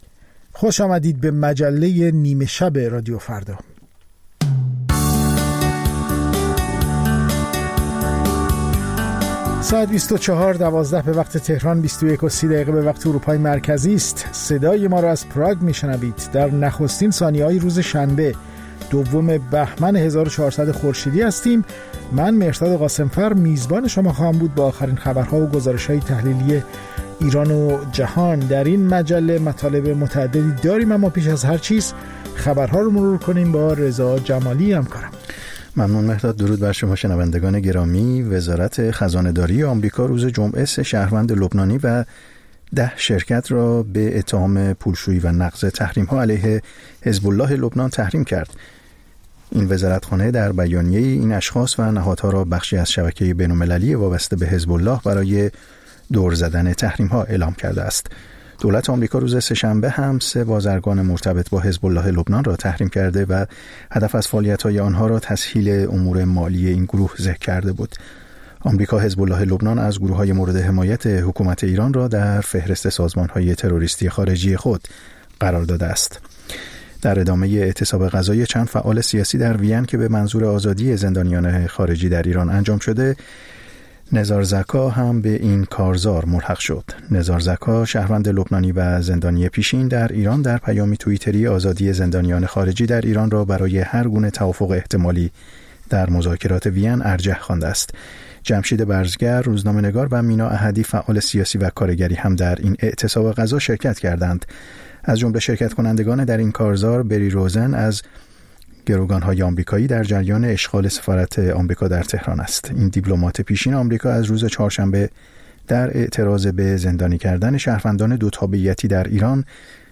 همچون هر روز، مجله نیمه‌شب رادیو فردا، تازه‌ترین خبرها و مهم‌ترین گزارش‌ها را به گوش شما می‌رساند.